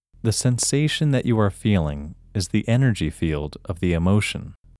IN – First Way – English Male 3
IN-1-English-Male-3.mp3